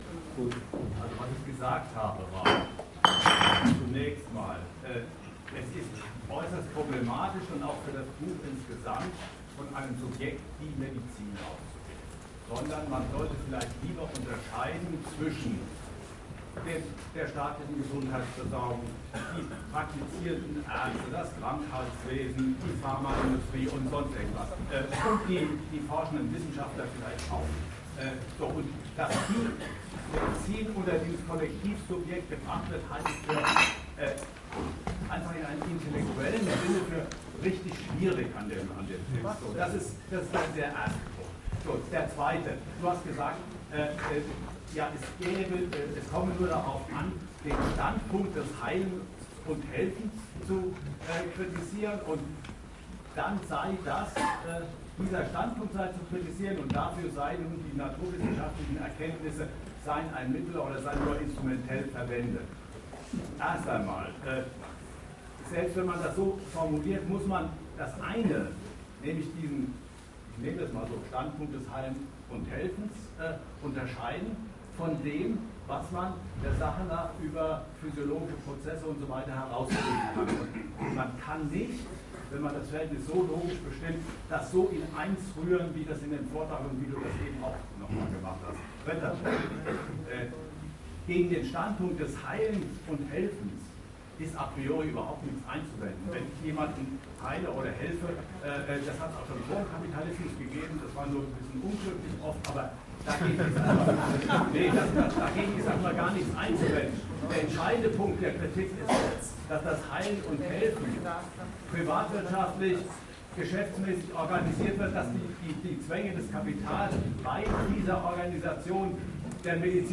Gesundheit - ein Gut und sein Preis (Buchvorstellung) | Argument und Diskussion